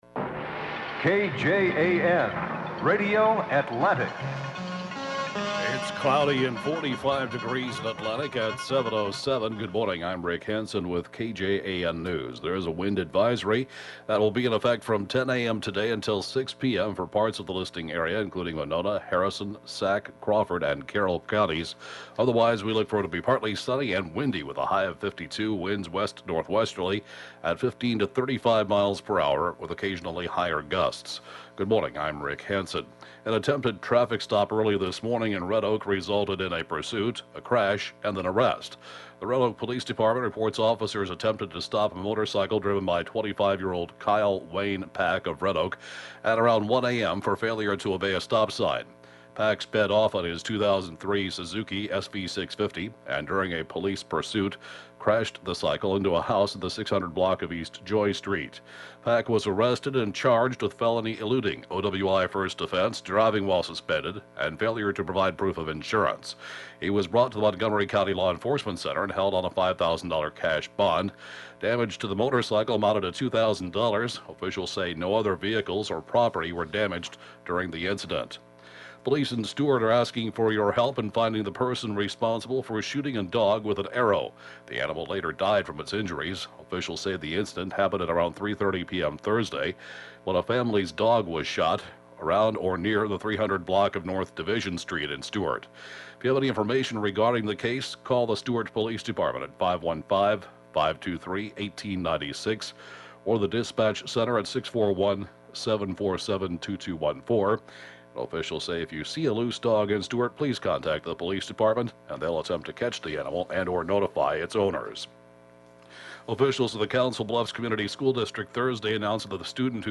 (Podcast) 7:07-a.m. News & funeral report, Fri., 10/3/2014